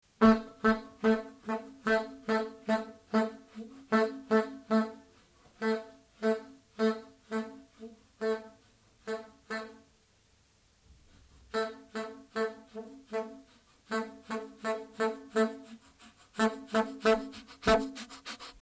ネットで見つけたホロフォニクスで録音されたと思われる音源。
ラッパを吹く音
Horn.mp3